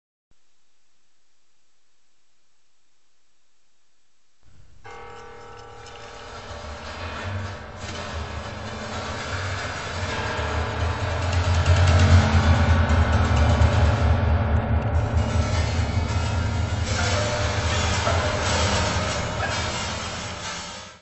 piano
: stereo; 12 cm
Music Category/Genre:  New Musical Tendencies